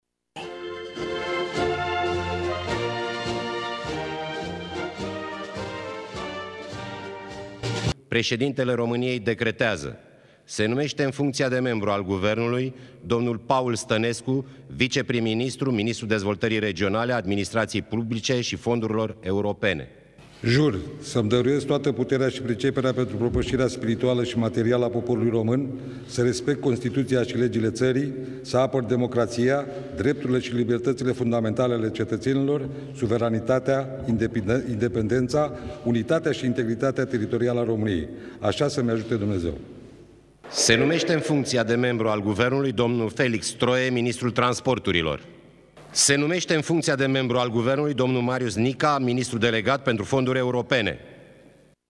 Cei trei noi miniștri au depus jurământul la Palatul Cotroceni
Ceremonia s-a desfășurat în prezenta Președintelui României, Klaus Iohannis, și a prim-ministrului, Mihai Tudose:
depunere-juramant.mp3